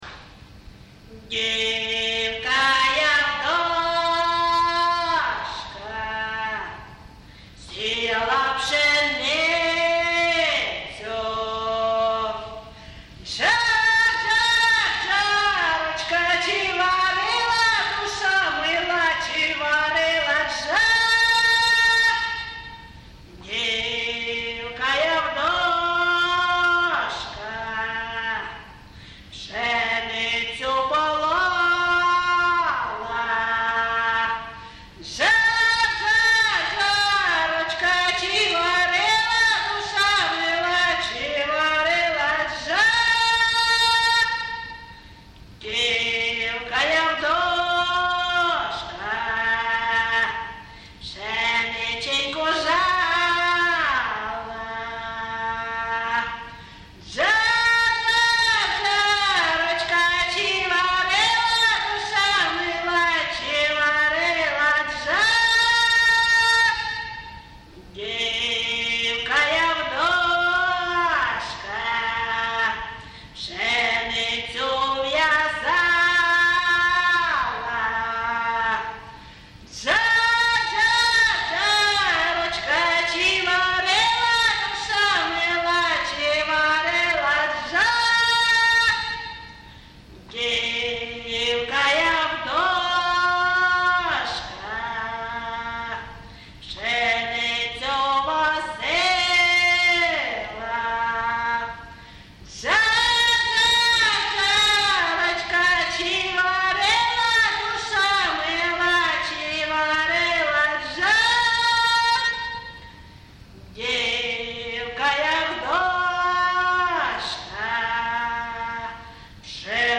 ЖанрПісні з особистого та родинного життя
Місце записус. Ковалівка, Миргородський район, Полтавська обл., Україна, Полтавщина